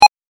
ボタン・システム （87件）